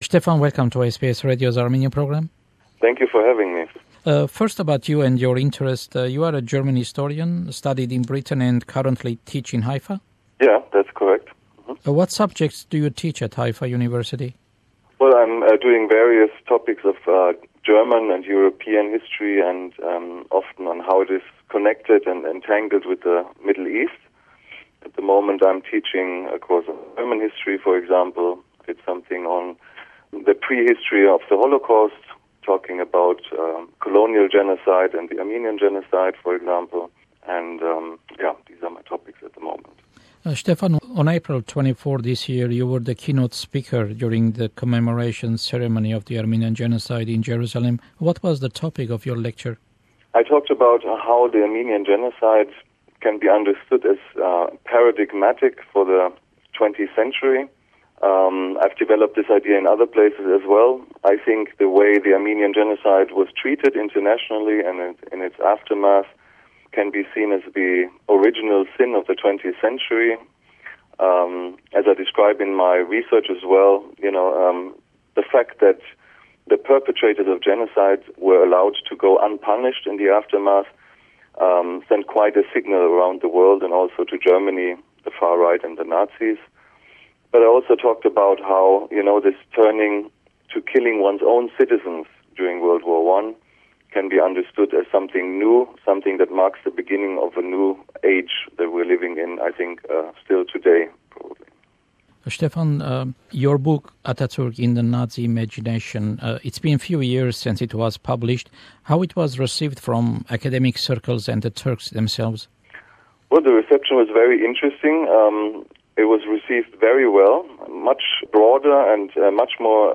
The first part of the interview mainly deals with his book Atatürk in the Nazi Imagination. He explains the reasons German nationalists and Nazis, after WWI, were fascinated with Ataturk.